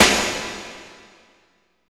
50.01 SNR.wav